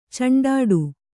♪ caṇḍāḍu